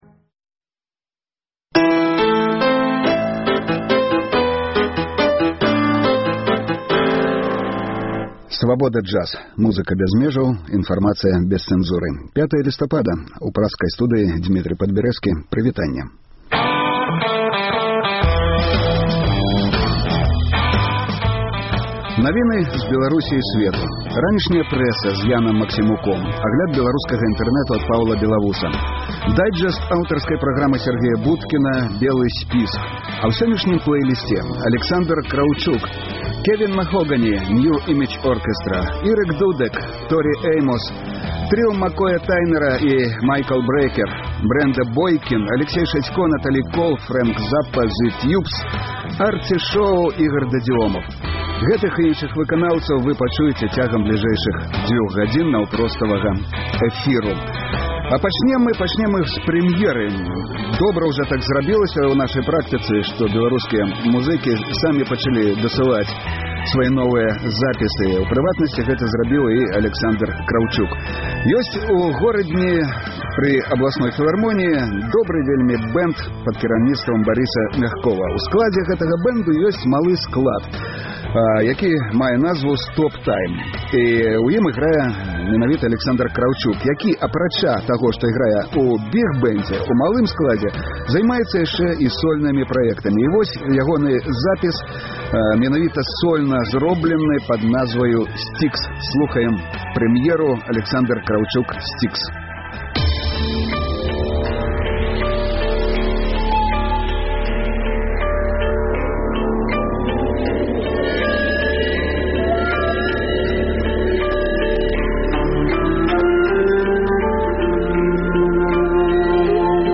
Інтэрнэт-радыё Svaboda Jazz. Слухайце ад 12:00 да 14:00 жывы эфір Свабоды!